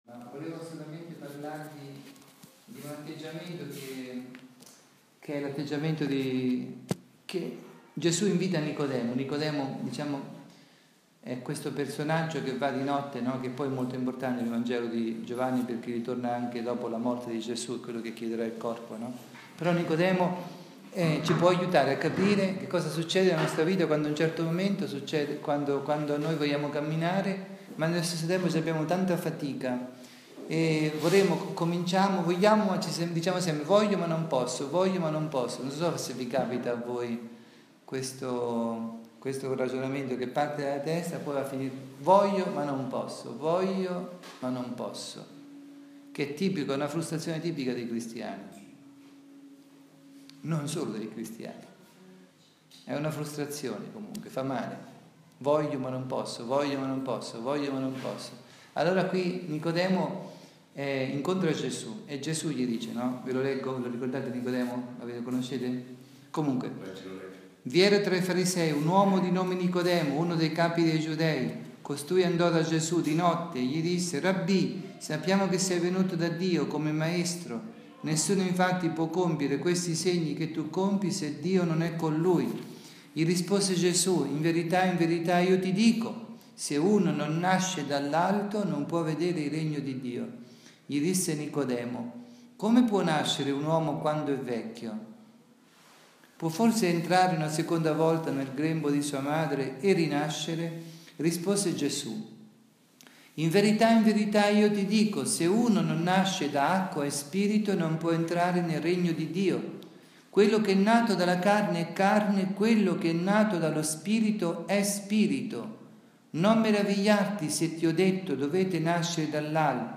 Catechesi
rivolta ad un gruppo di famiglie in ritiro presso il Santuario di San Nicola da Tolentino. (Vangelo di Giovanni, capitolo 3, 1-8)